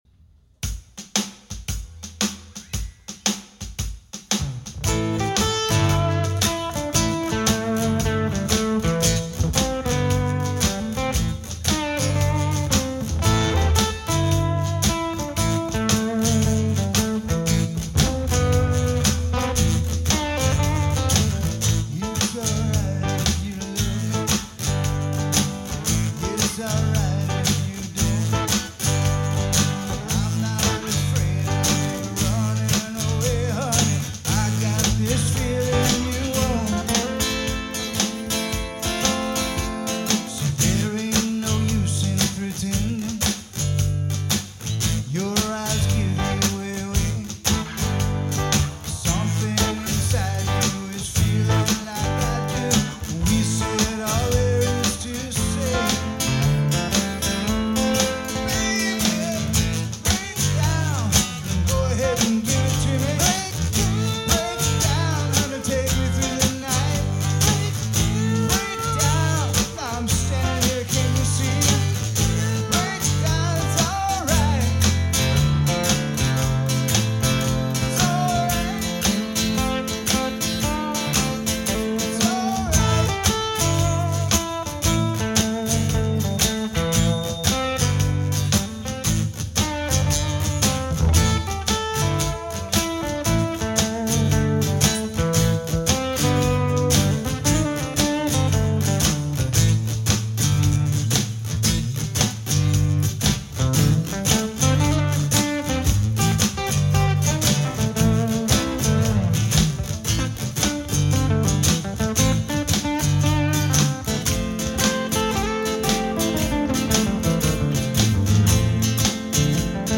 good vibes, great crowd, and plenty of energy to go around.